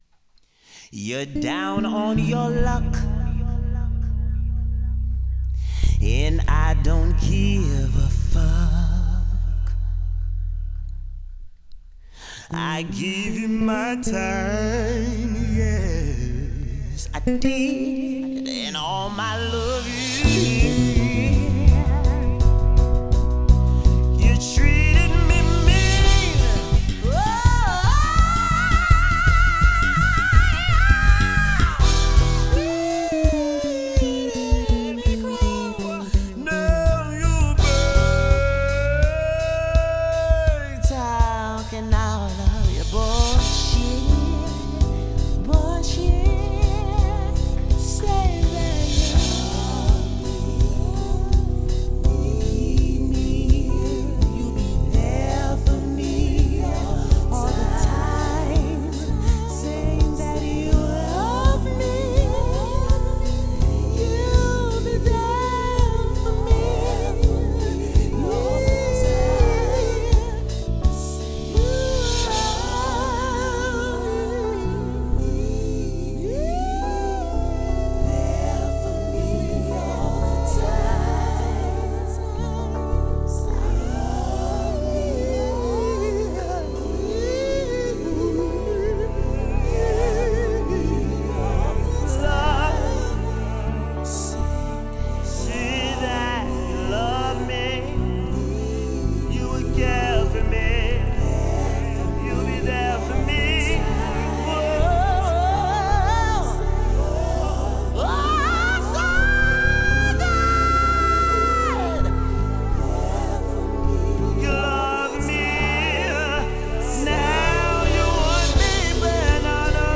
Bass Guitar